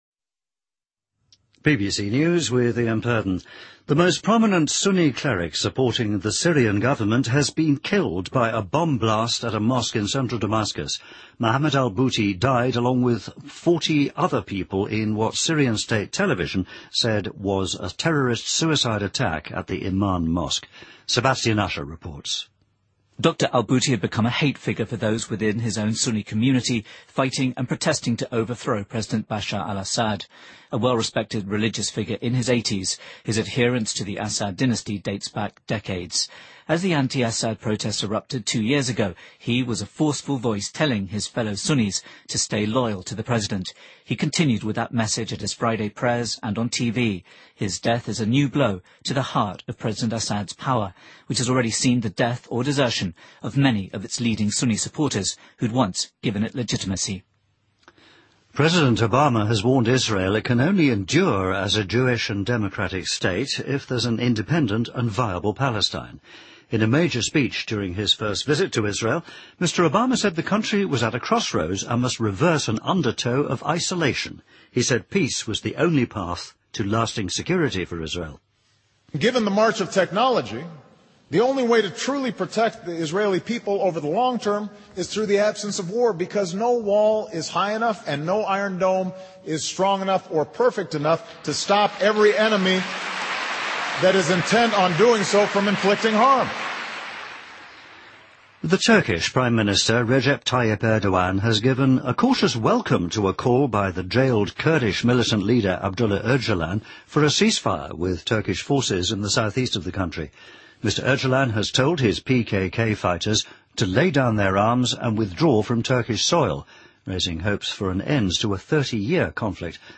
BBC news,2013-03-22